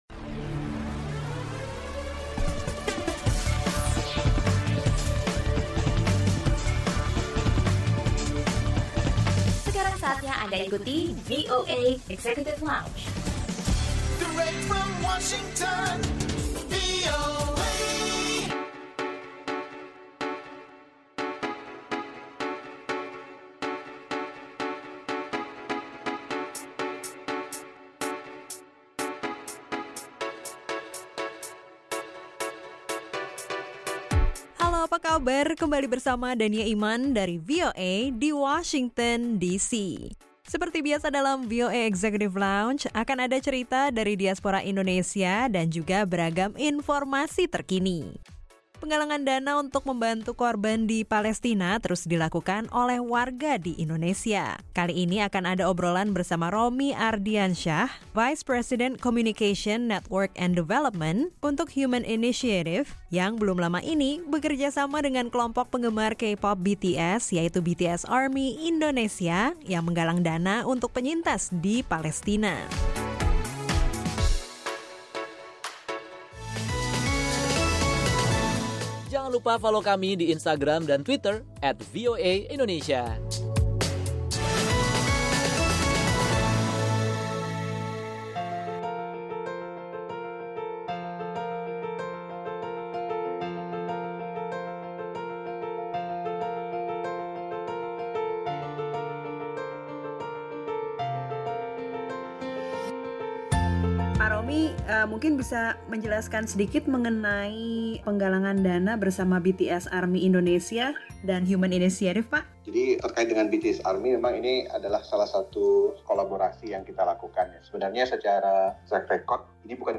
Simak obrolan bersama Vice Presiden Communication